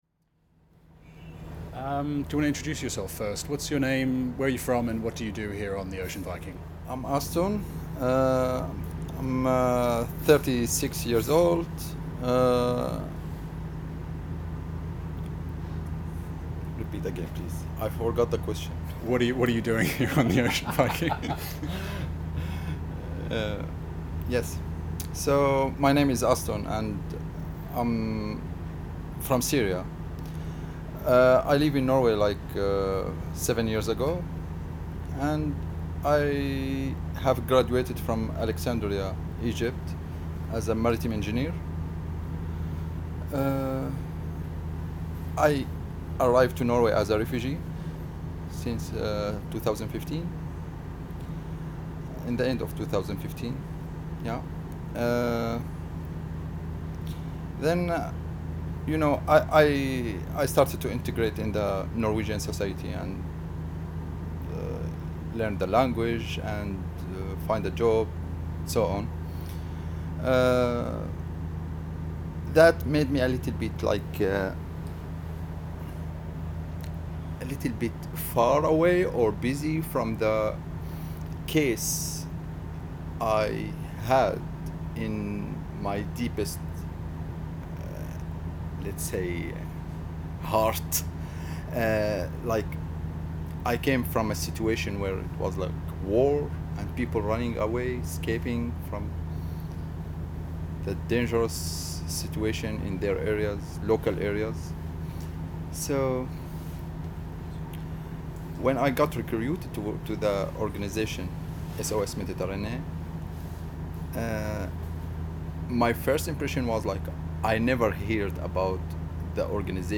Interview with a Syrian rescue worker
Part of the Migration Sounds project, the world’s first collection of the sounds of human migration.